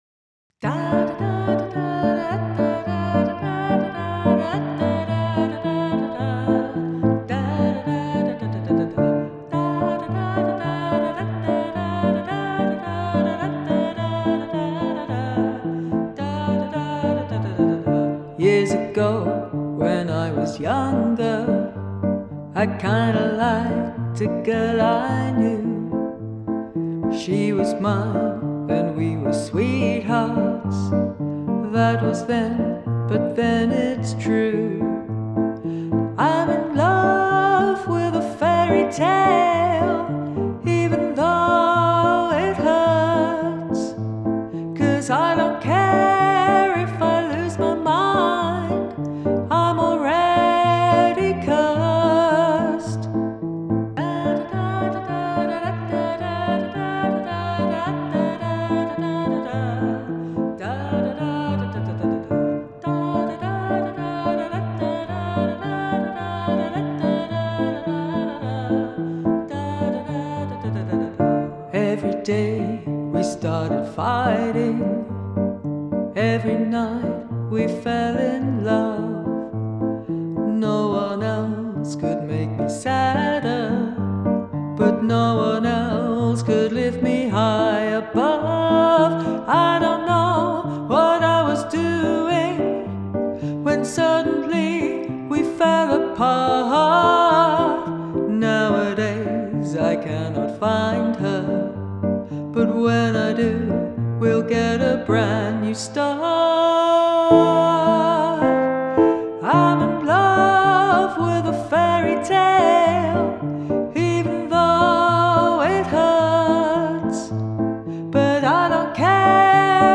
you put a marlene dietrich twist on this one. very cabaret.